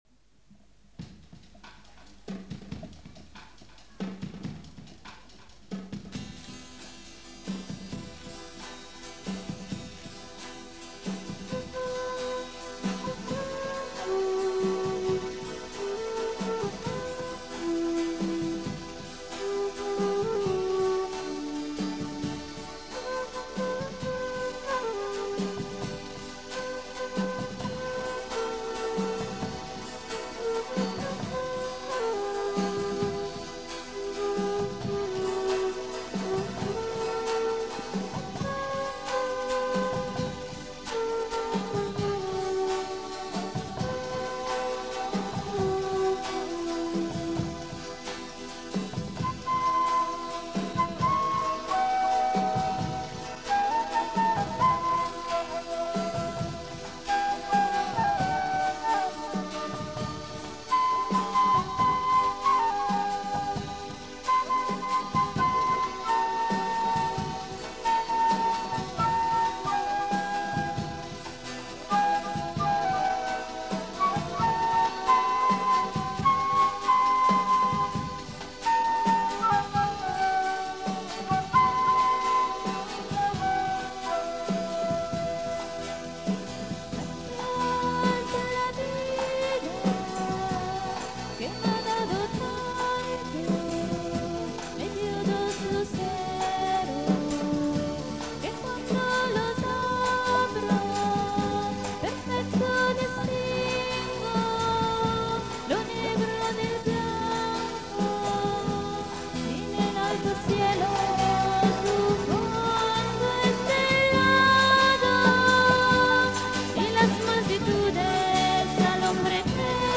Download   CD live (1983-1993) del 1998